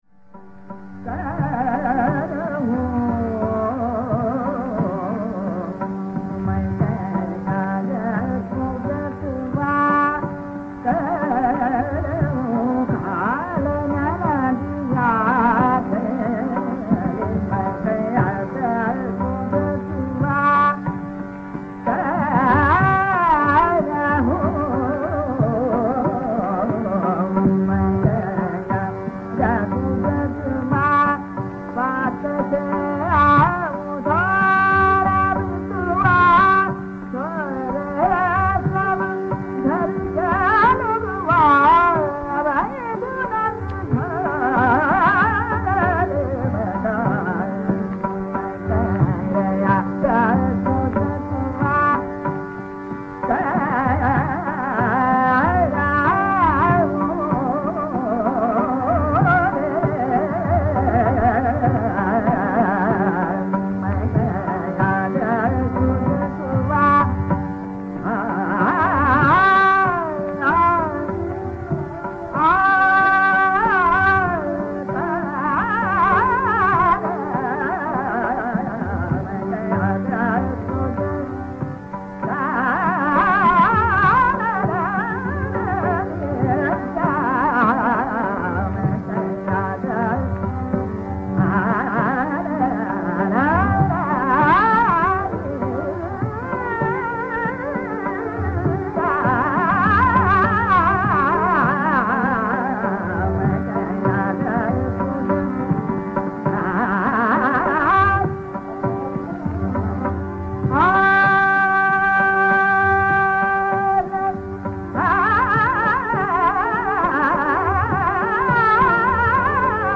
The key idea in Sughrai is the intertwining of the Kanada and Sarang angas.
Kesarbai Kerkar sings another traditional Sughrai bandish.